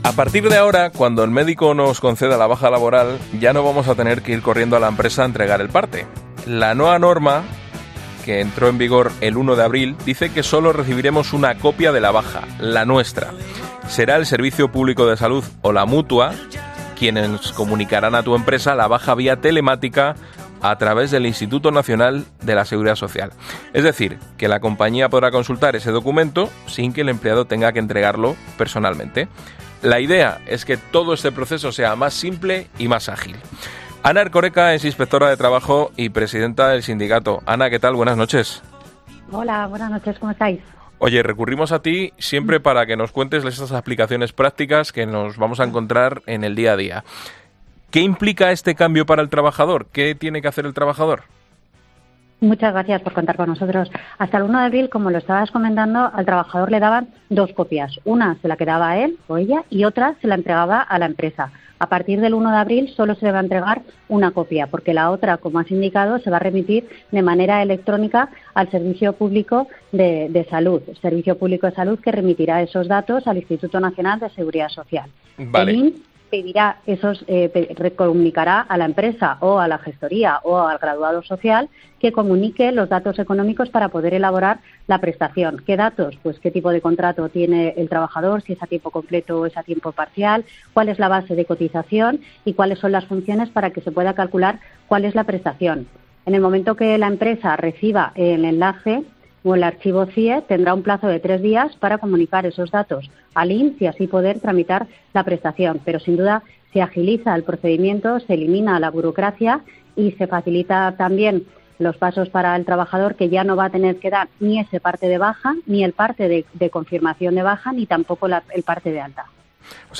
Una inspectora de Trabajo aclara qué pasa si necesito una baja laboral por un día porque me encuentro mal